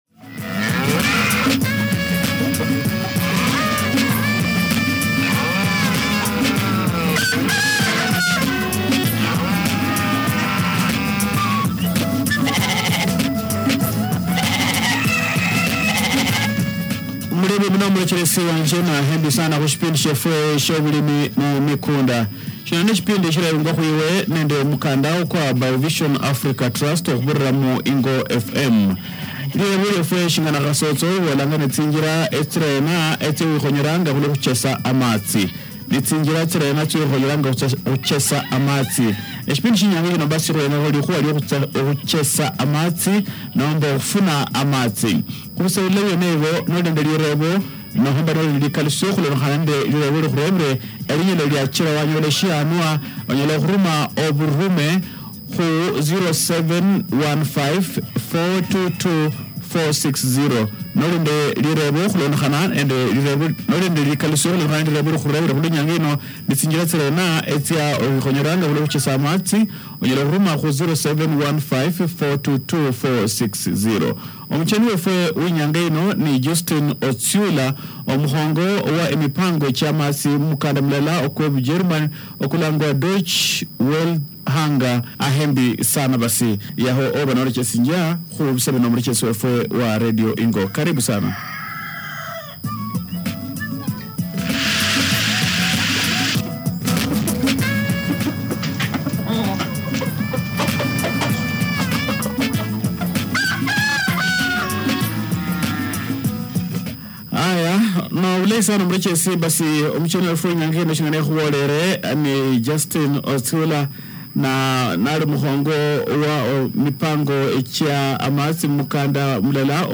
In this enlightening studio interview, we dive into the essentials of water harvesting and its critical role in transforming agriculture in Western Kenya.